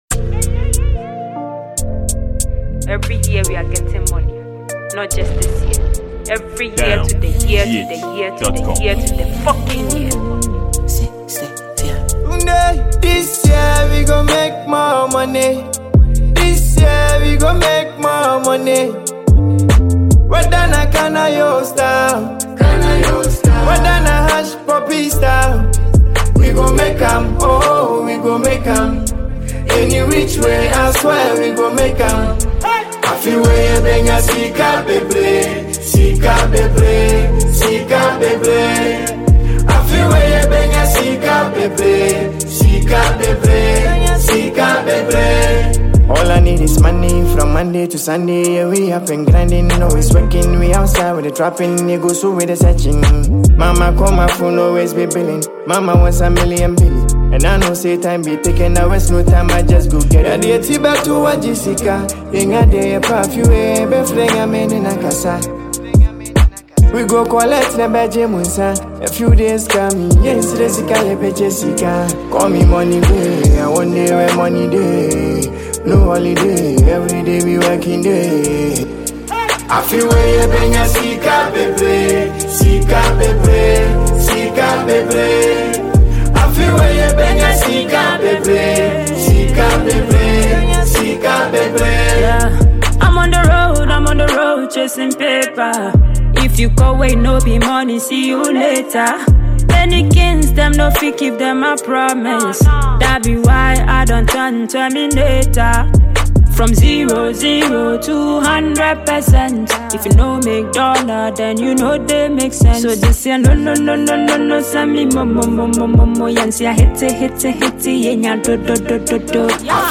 a fast-rising Ghanaian afrobeat singer